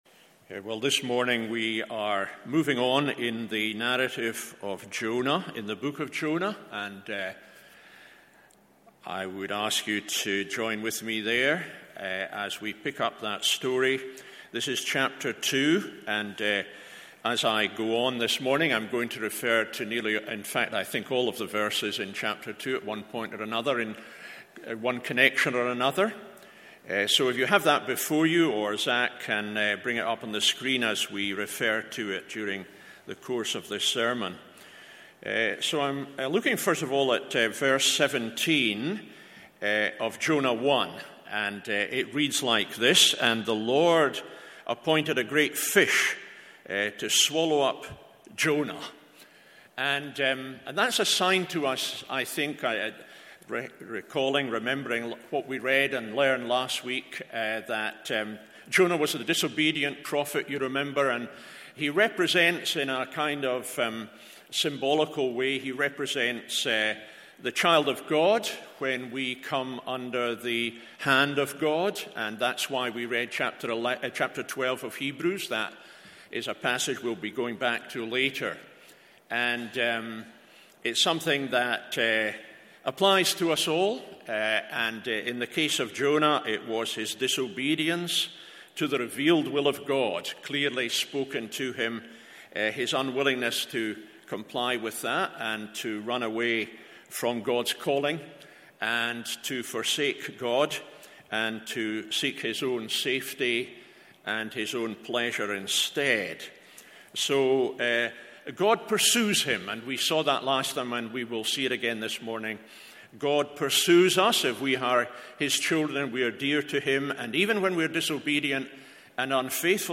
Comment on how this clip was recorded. MORNING SERVICE Jonah 2…